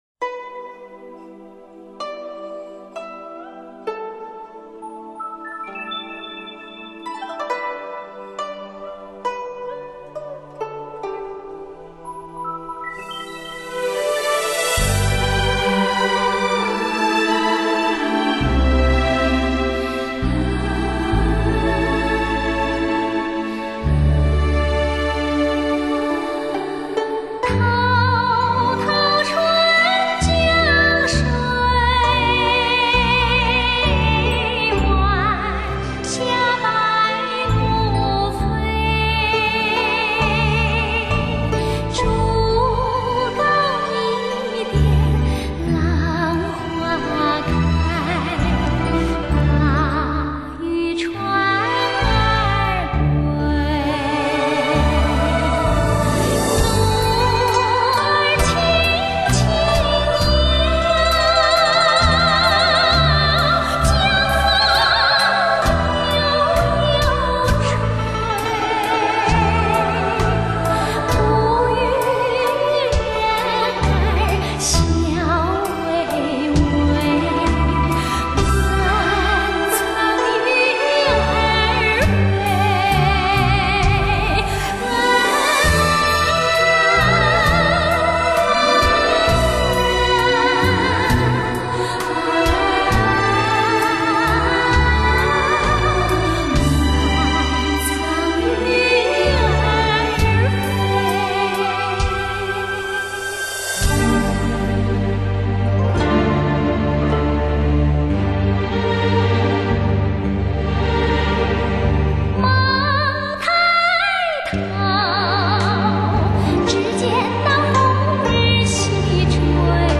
古曲改编